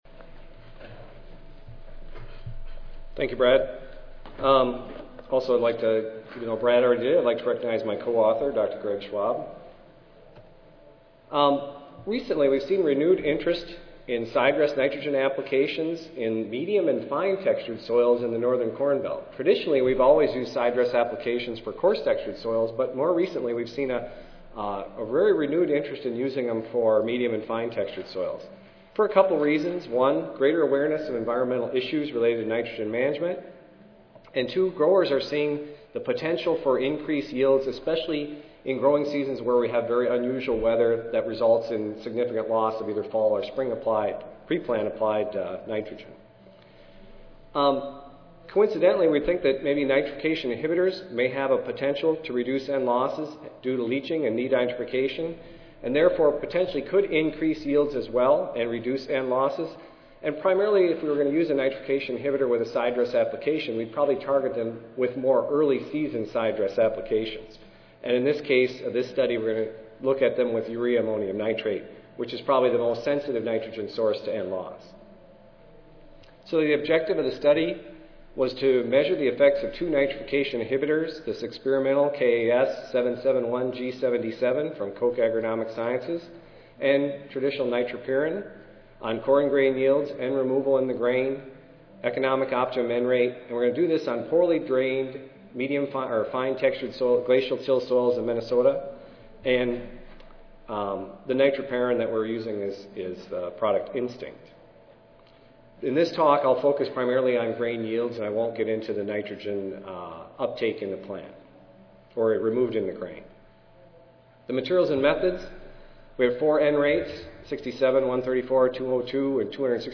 LLC Audio File Recorded Presentation